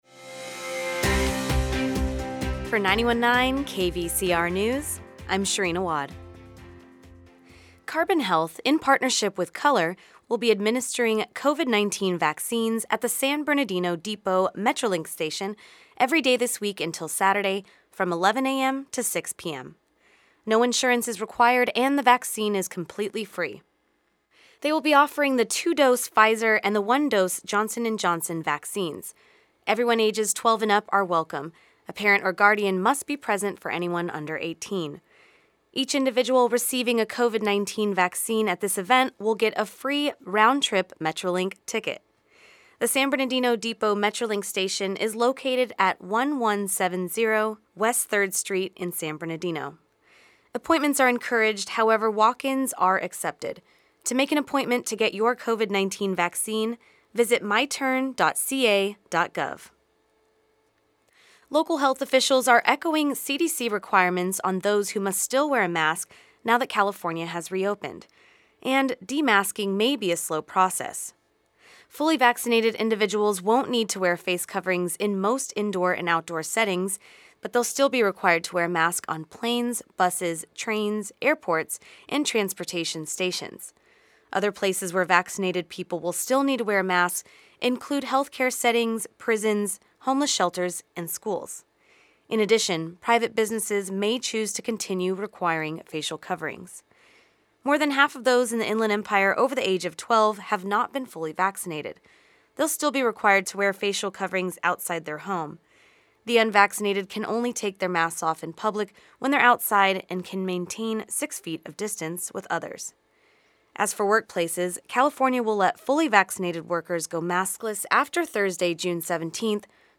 The Midday News Report